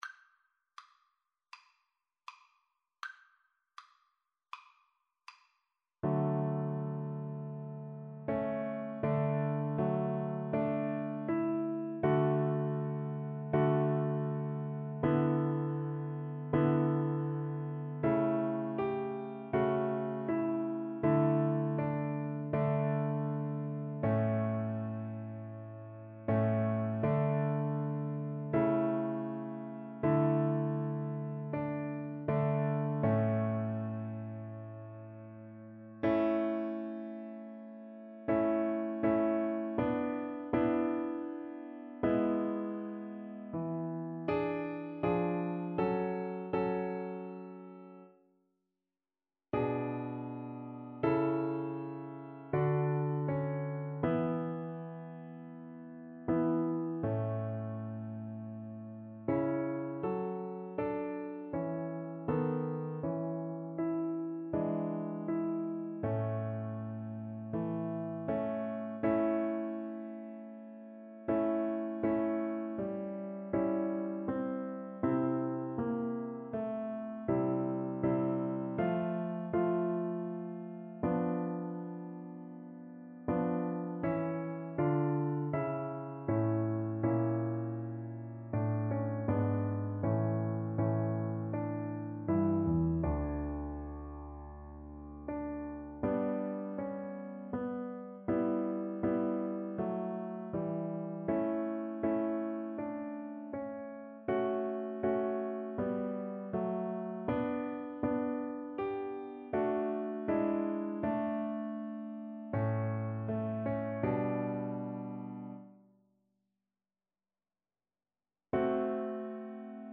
Play (or use space bar on your keyboard) Pause Music Playalong - Piano Accompaniment transpose reset tempo print settings full screen
Violin
D major (Sounding Pitch) (View more D major Music for Violin )
Adagio = c. 40
Classical (View more Classical Violin Music)